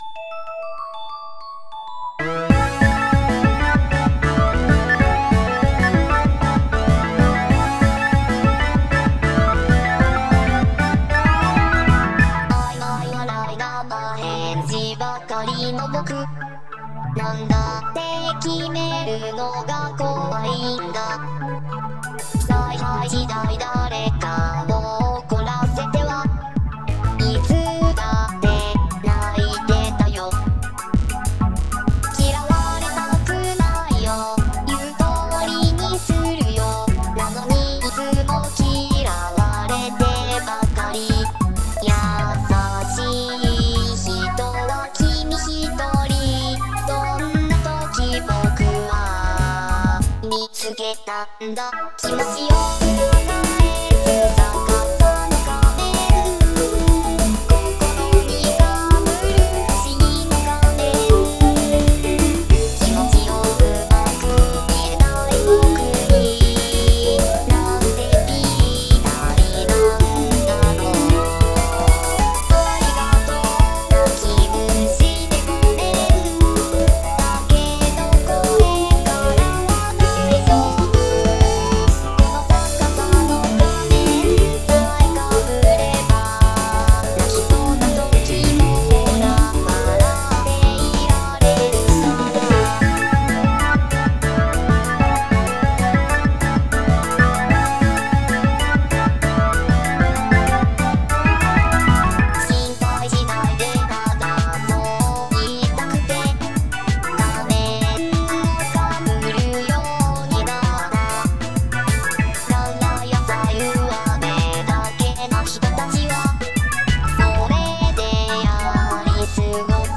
UTAU